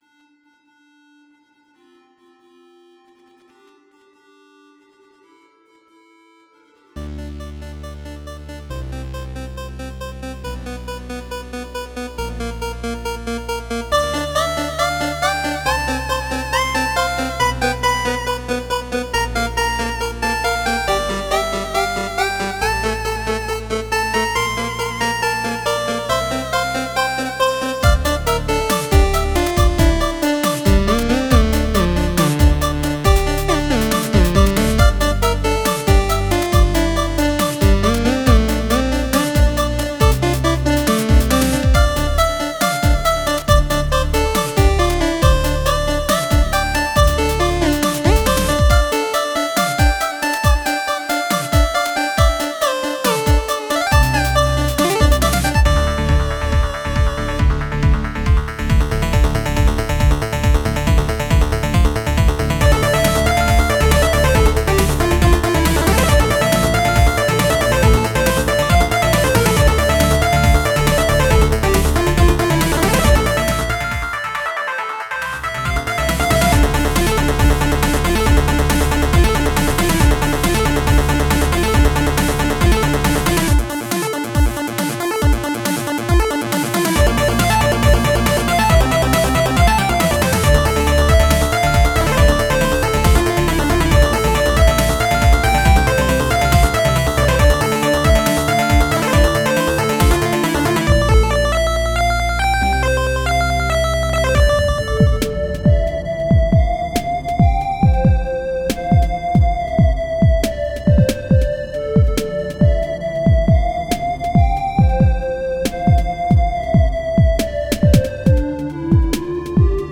Another one of my chiptune fusion pieces.